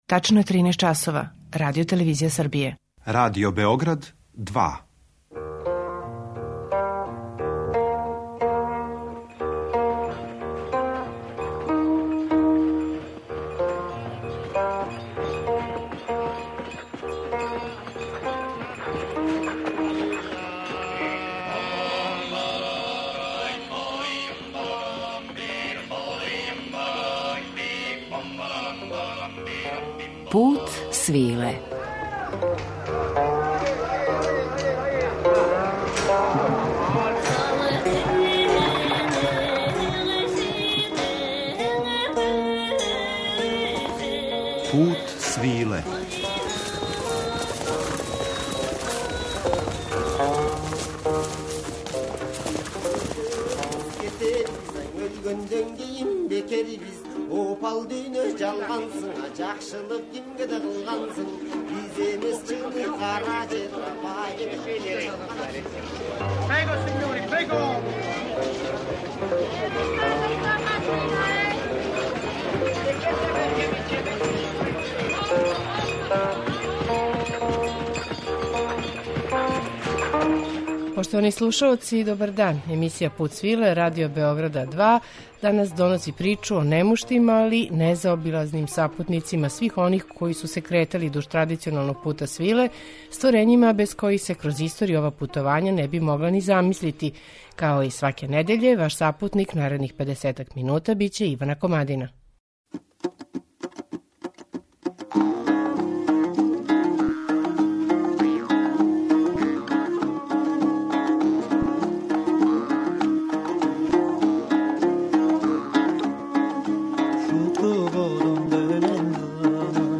Основна одлика тог ритма јесу његова флуидност, честе промене фигура и варирање дужина фраза.